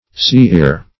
Sea-ear \Sea"-ear`\ (s[=e]"[=e]r`), n. (Zool.)